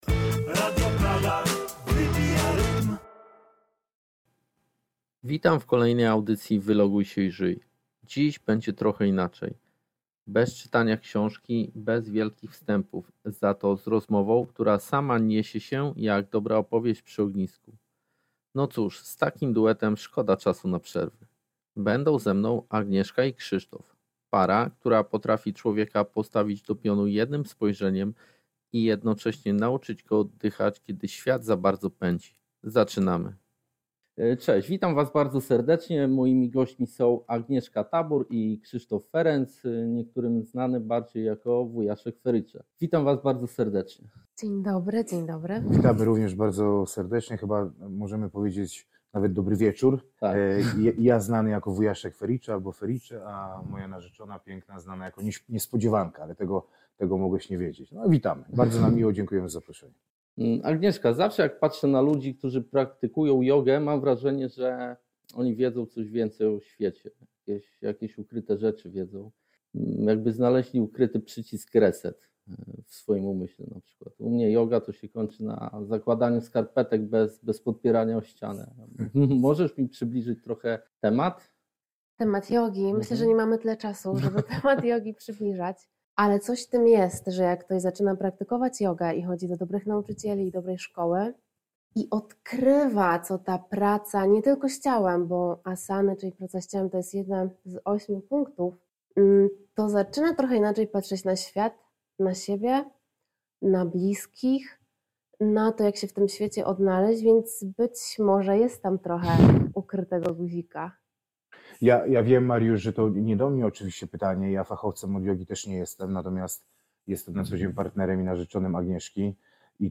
To rozmowa naturalna, szczera, momentami mocna, momentami bardzo spokojna.